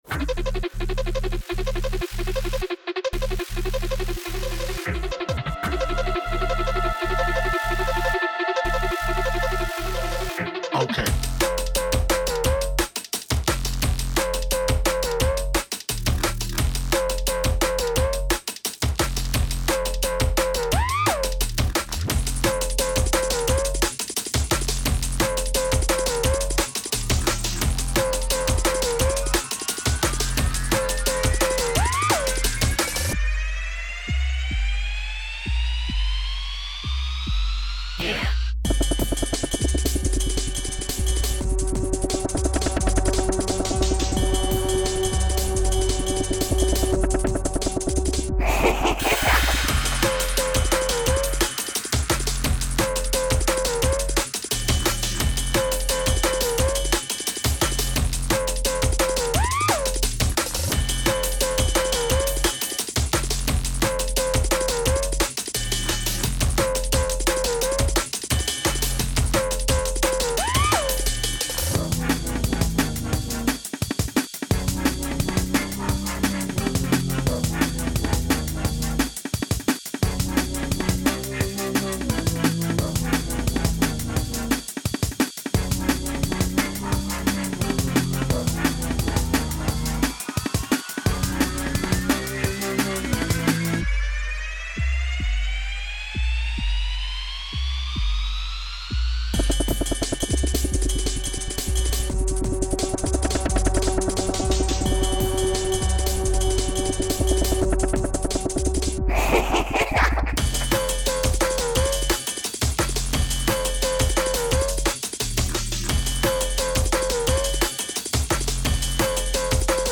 Genre Drum & Bass